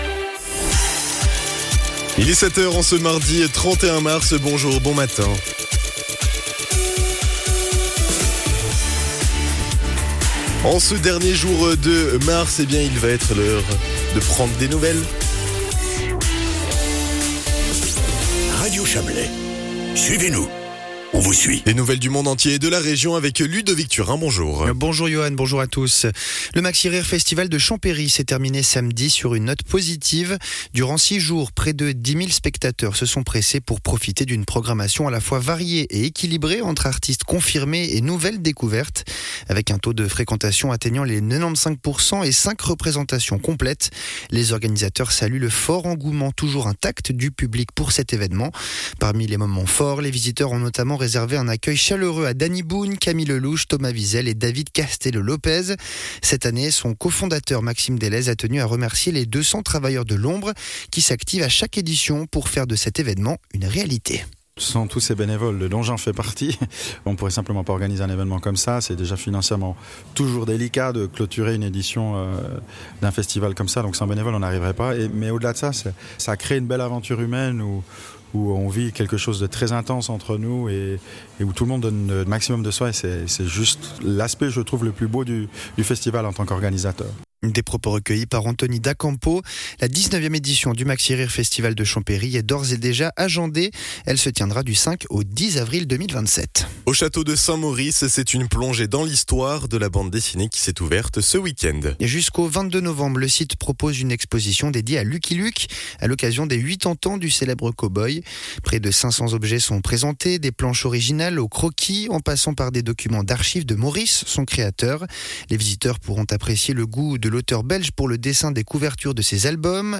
Le journal de 7h00 du 31.03.2026